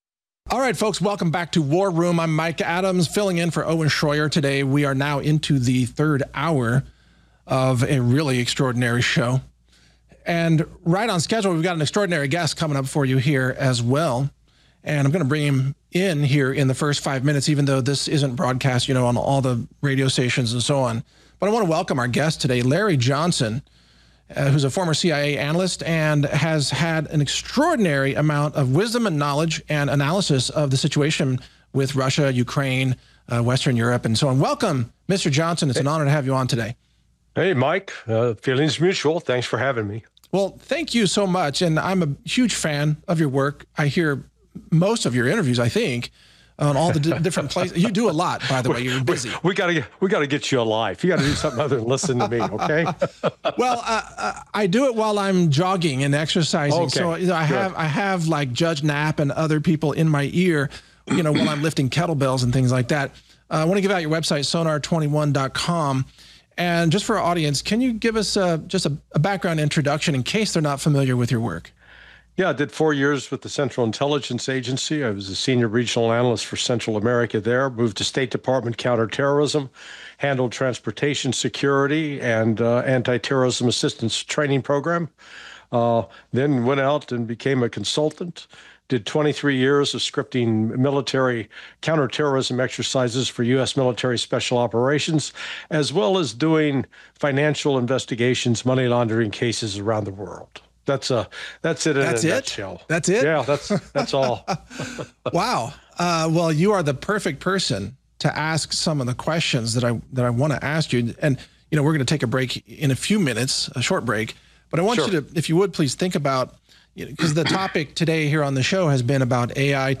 - Introduction and Guest Introduction (0:00)